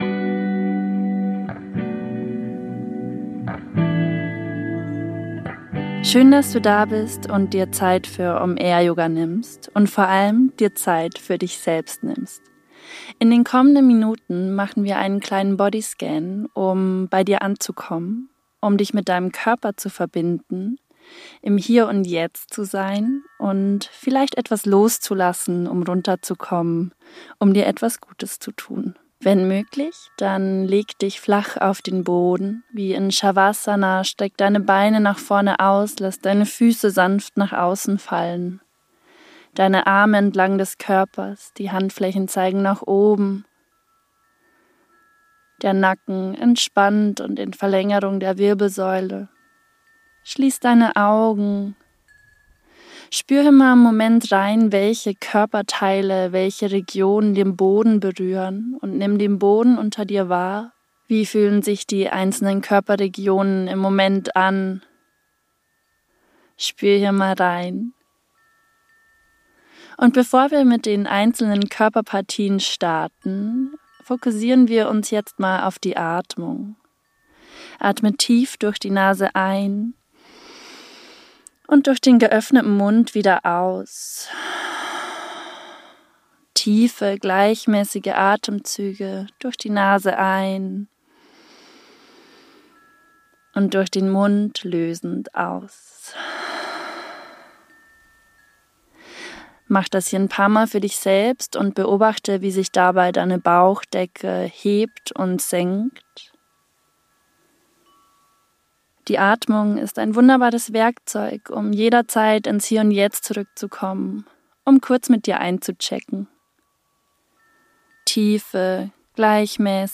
Bodyscan: geführte Achtsamkeitsübung | 13 min ~ OM AIR YOGA Podcast